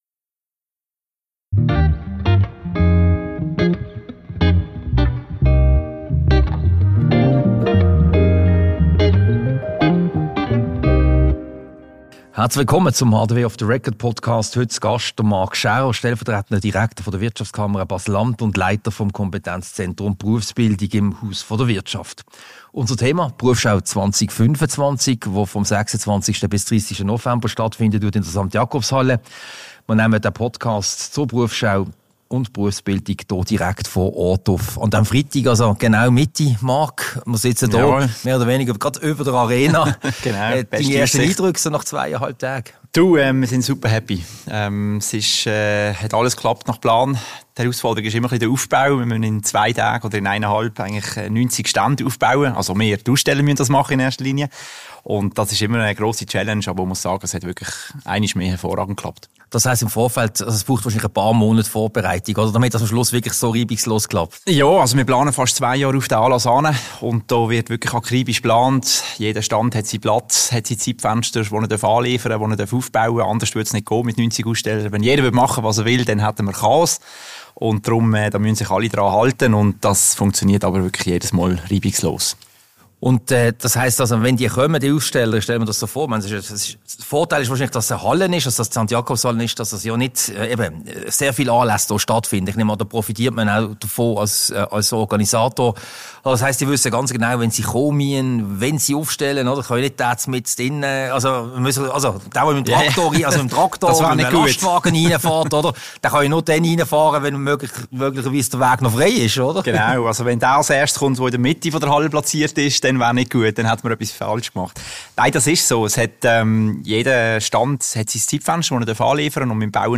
Diese Podcast-Ausgabe wurde an der Berufsschau 2025 aufgezeichnet.